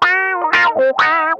ITCH LICK 6.wav